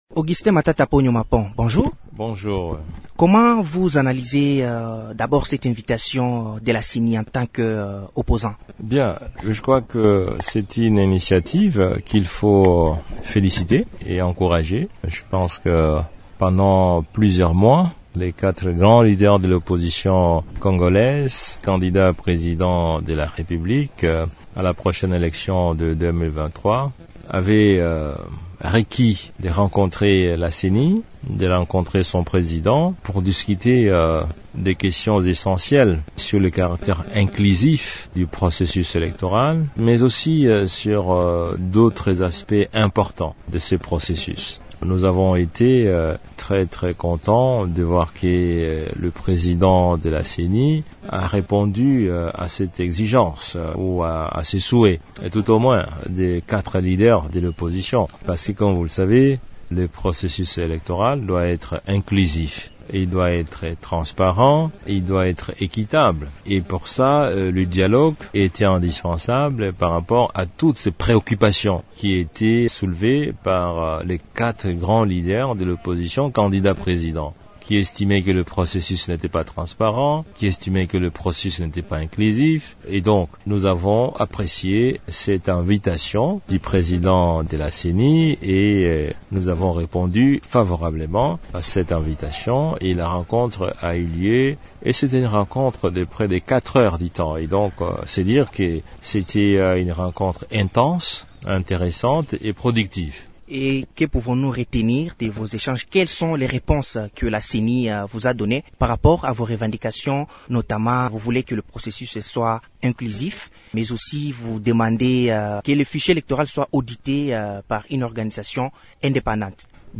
interview_integrale_avec_matata_ponyo_web.mp3